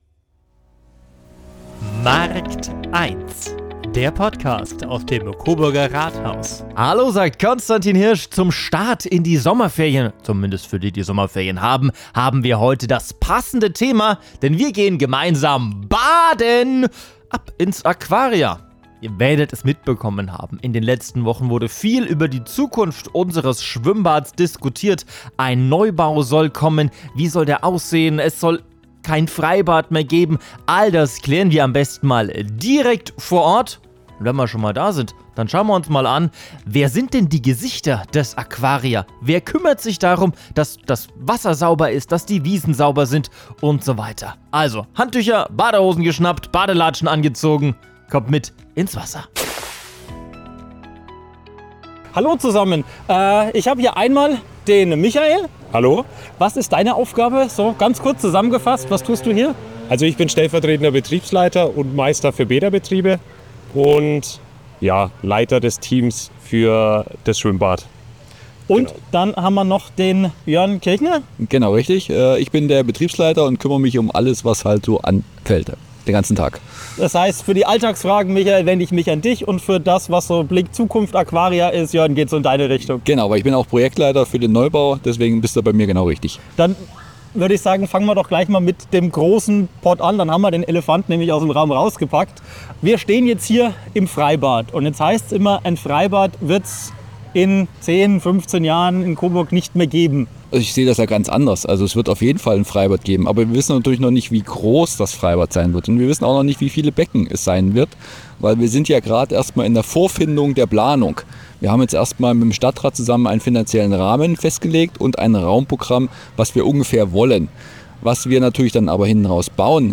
Wir sprechen mit den Verantwortlichen über die Zukunft samt Neubau. Und klären, wie viel "Baywatch" wirklich im Alltag steckt.